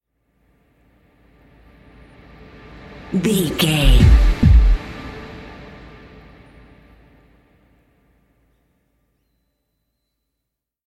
Ionian/Major
D
synthesiser
drum machine
ominous
dark
suspense
haunting
creepy